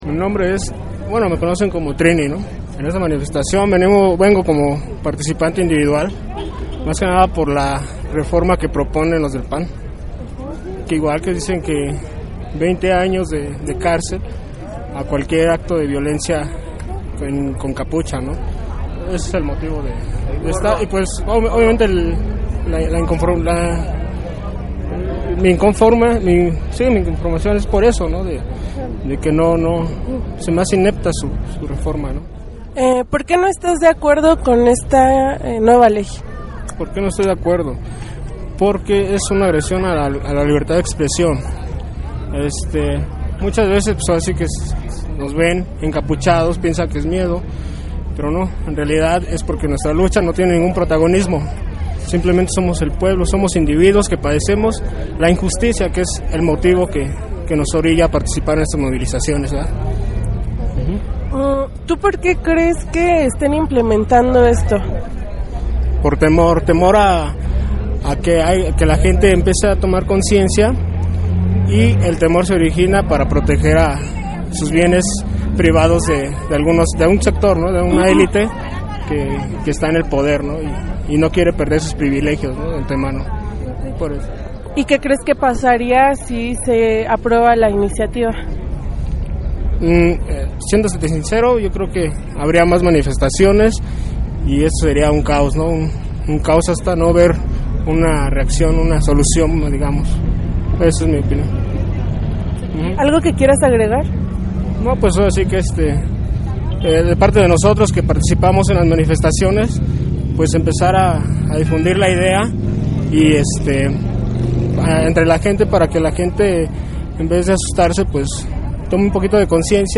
Entrevista_3.mp3